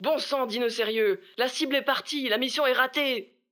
VO_ALL_EVENT_Temps ecoule_01.ogg